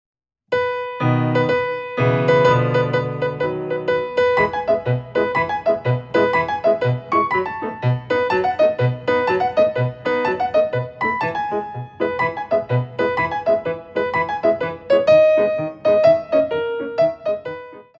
4x8 6/8